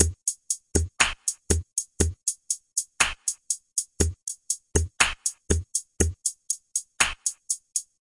嘻哈节拍120bpm " 节拍120bpmtest01
Tag: 回路 120BPM 节拍 啤酒花 量化 节奏 髋关节 有节奏